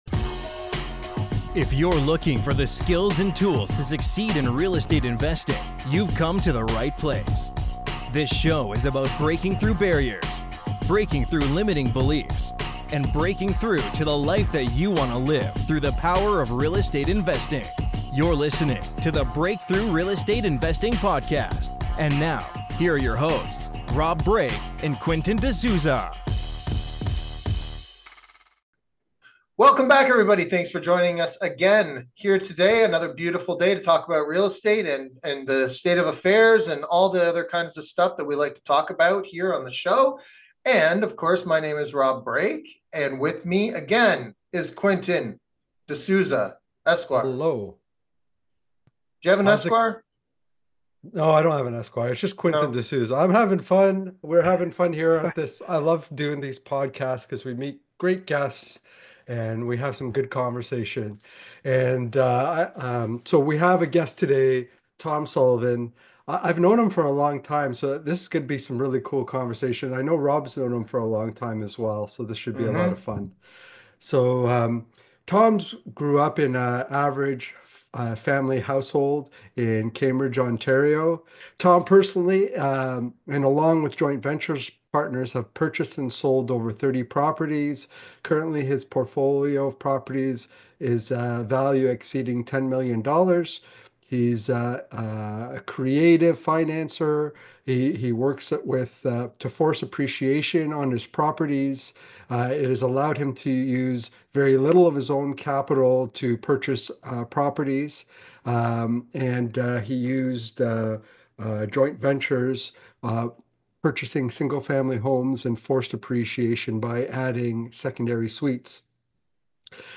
Episode #224: Tenants, Toilets, Partners and Evolving Strategies. Nuts and Bolts Real Estate Talk